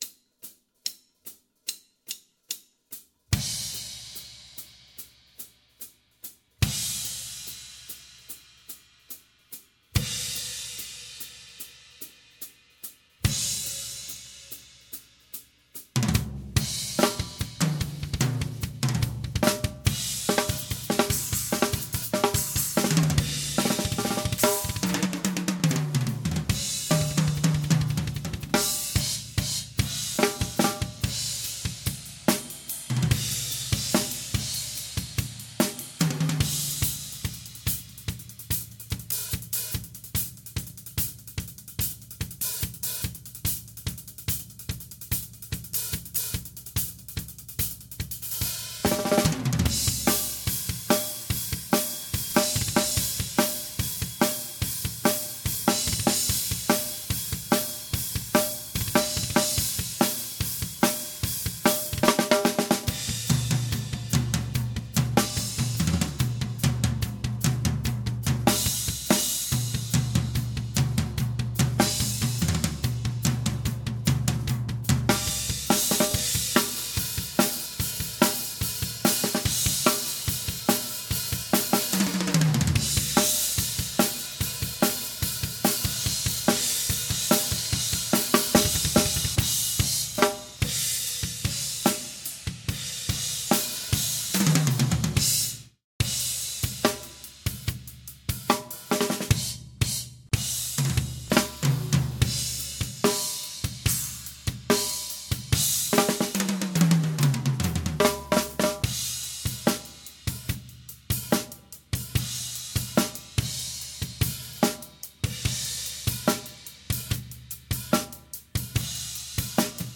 14" Mist Brilliant Hats (main), 13" Mist Brilliant Hats (remote), 15" Mist Brilliant Crash, 16" Mist Brilliant Crash, 17" Mist X Crash, 18" Mist X Crash/Ride, 20" Mist Brilliant Ping Ride